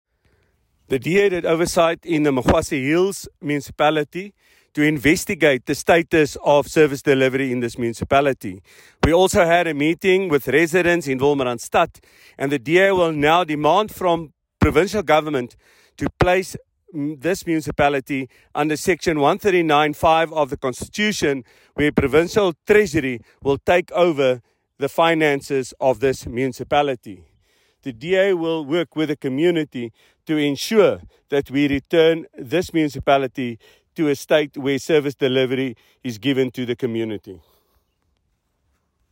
Note to Editors: Please find soundbites in
English and Afrikaans by Leon Basson MP and